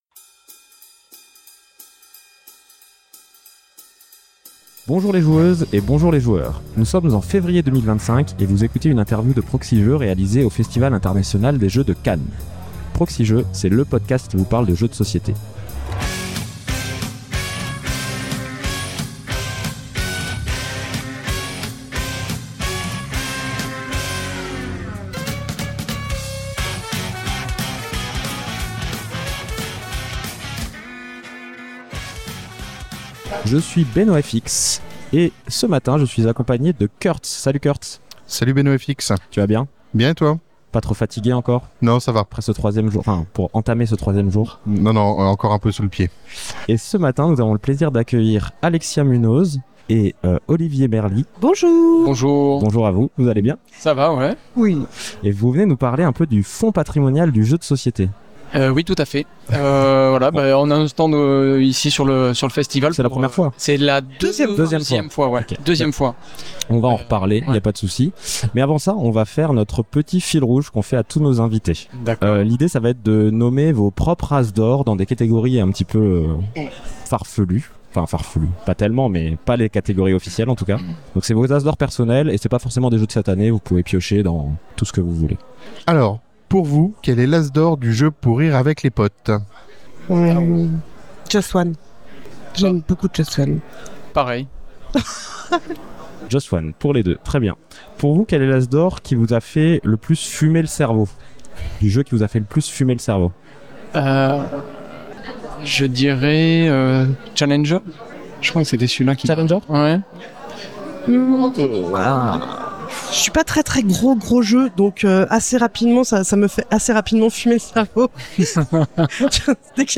Lors de son séjour au Festival International des Jeux de Cannes, la Proxi-Team a rencontré de nombreuses actrices et de nombreux acteurs du monde du jeu de société.